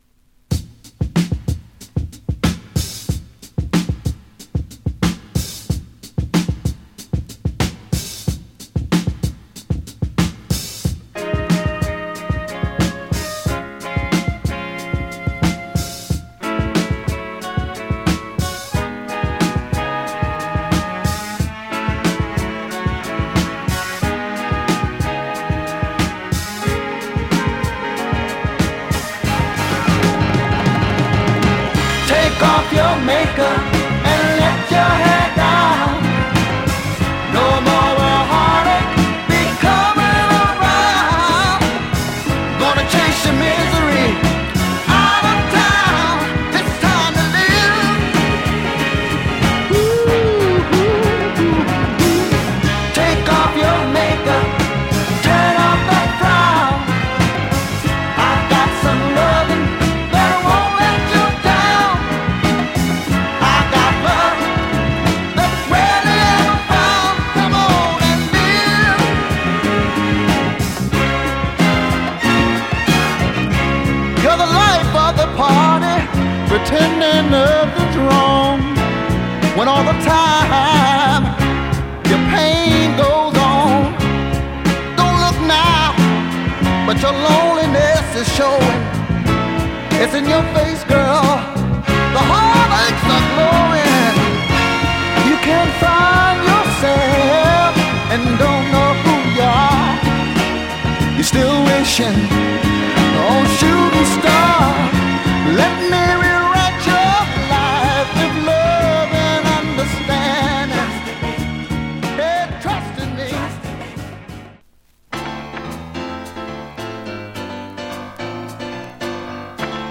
SOUL
扇情的なピアノに、爽快なヴォーカルが弾ける、抜群にグルーヴィー…